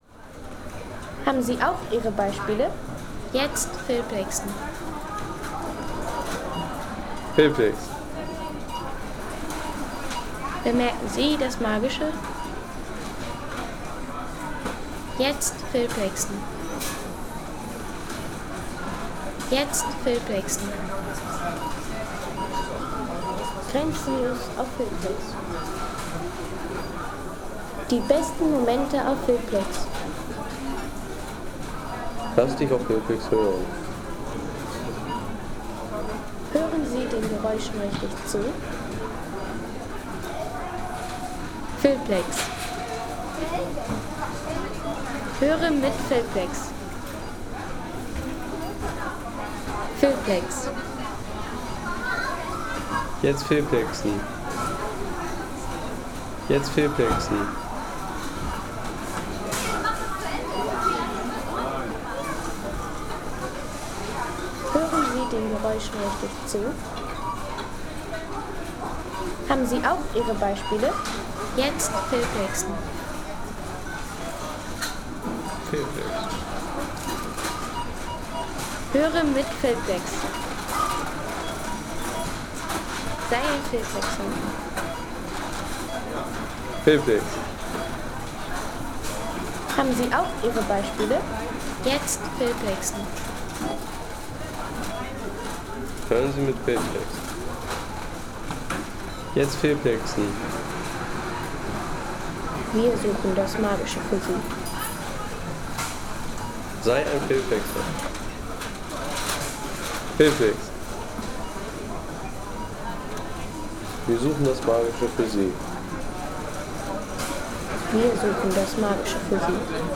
Seien Sie der Erste, der dieses Produkt bewertet Artikelnummer: 207 Kategorien: Menschen - Shopping Erlebe die festliche Stimmung!
Weihnachtseinkaufserlebnis an der deutsch-dänischen Grenze – Hör d ... 3,50 € Inkl. 19% MwSt.